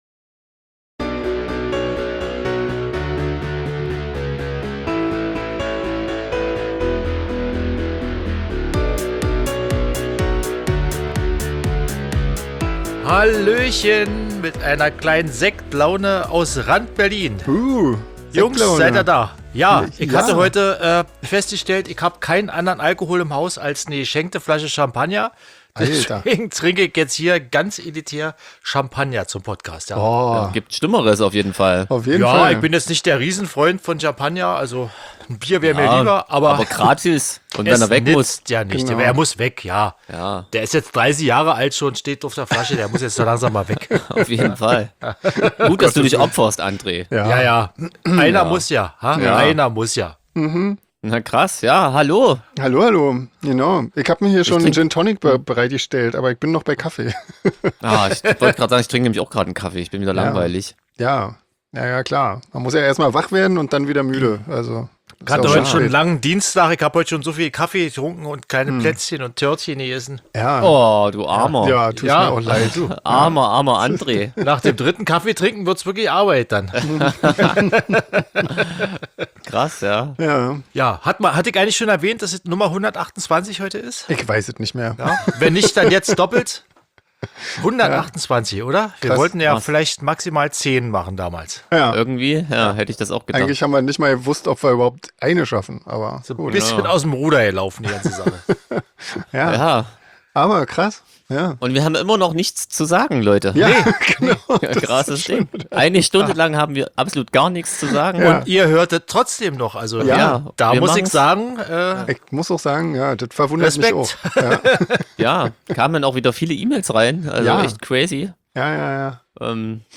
Stellt Fragen an die Bandmitglieder, schlagt ihnen Themen vor, über die sie reden sollen oder freut Euch einfach über die Gespräche der 3 Musiker.